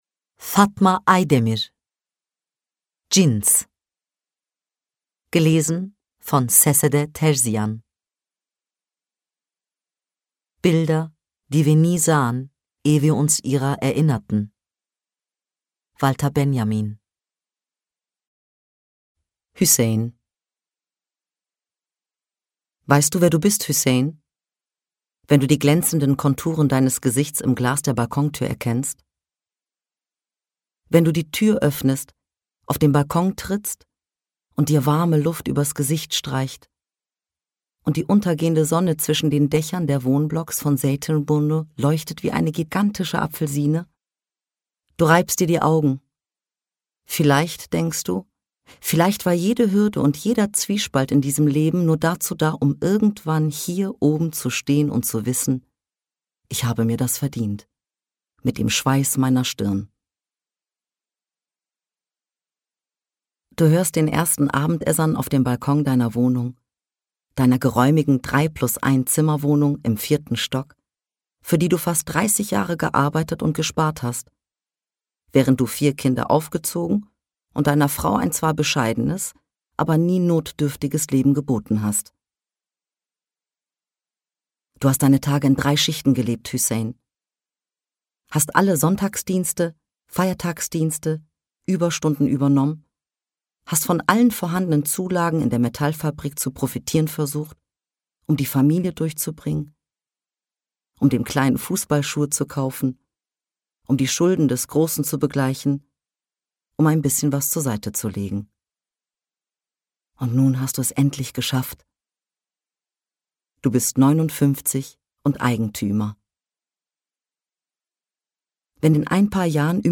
2022 | Ungekürzte Lesung